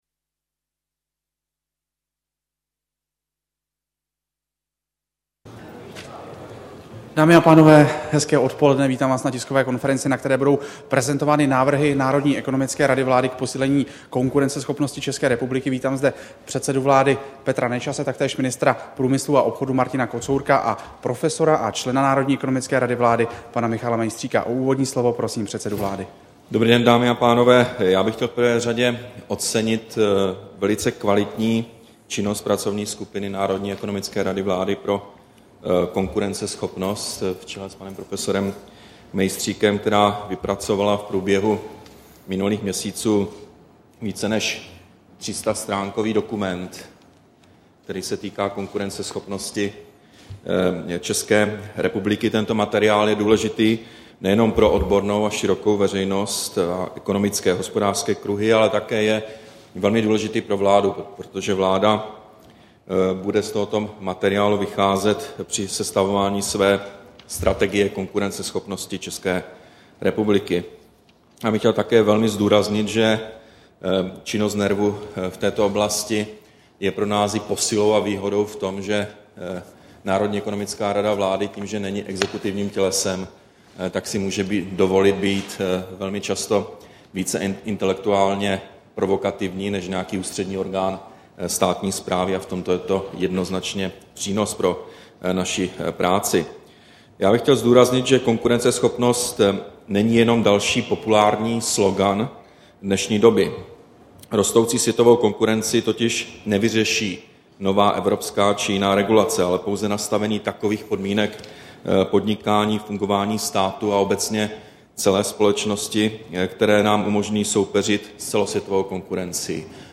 Tisková konference Nerv: Konkurenceschopnější Česko, 17. února 2011